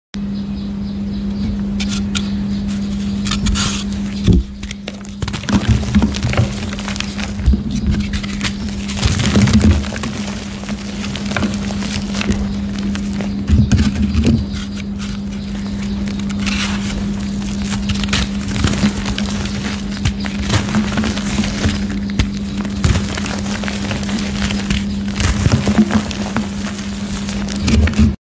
crush queen asmr 😍 sound effects free download